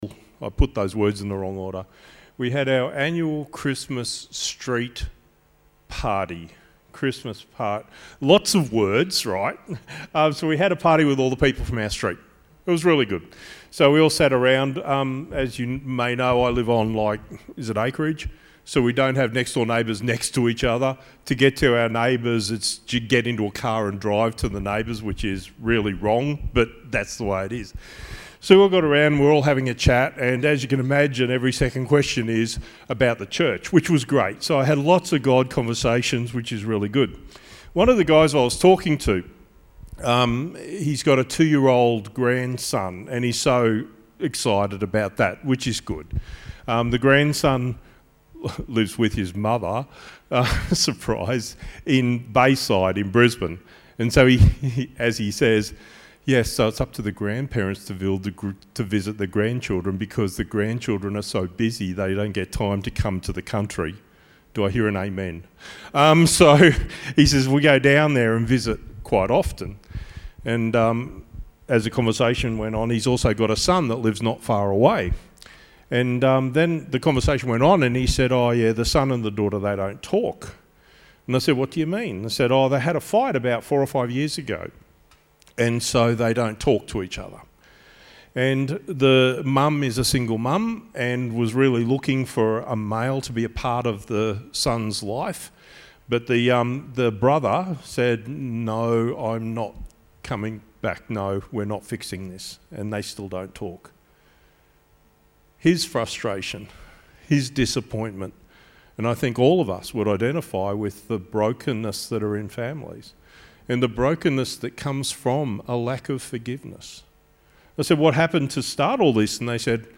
Psalm 103: 8 - 14; Luke 4: 1 - 13; Communion Service; Songs: Hark the Herald Angels, 10,000 Reasons, How Deep the Father's Love sung prior to the message.